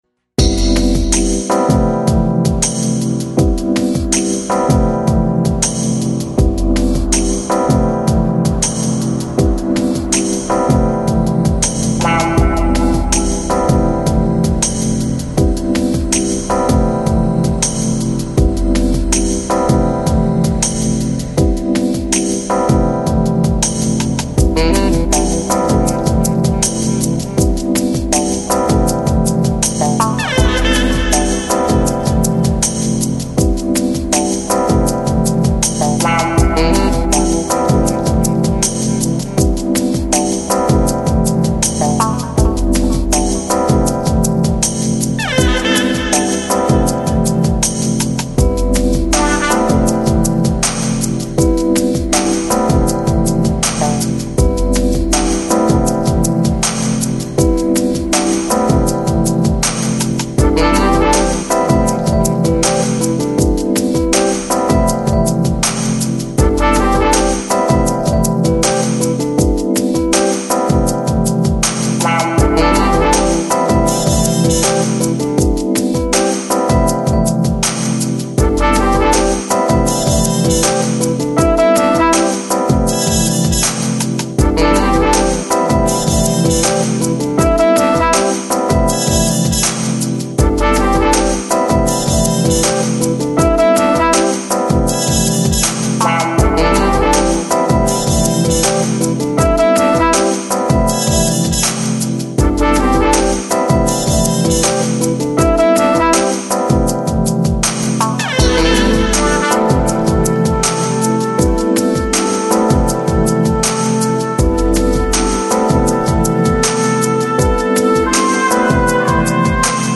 Downtempo, Lounge, Chillout Носитель